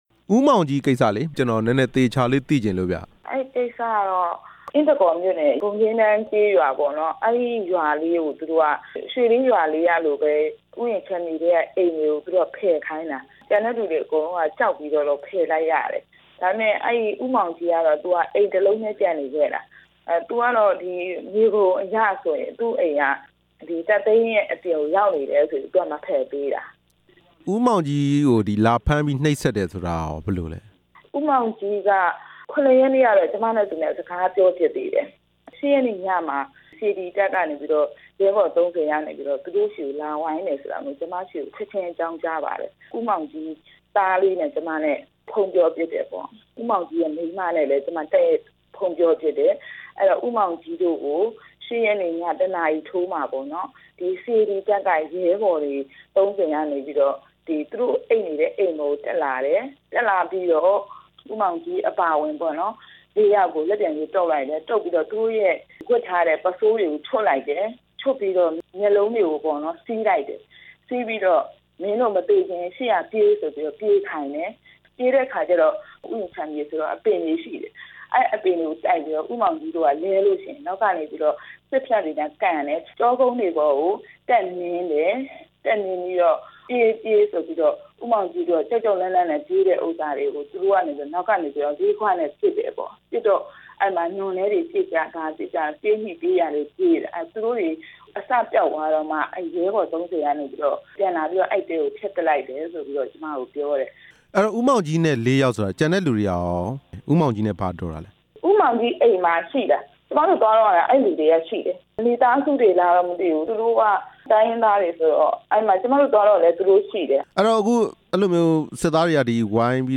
ဂုံညင်းတန်းရွာသား ၄ ဦးကို စစ်တပ်နှိပ်စက်မှု ဆက်သွယ်မေးမြန်းချက်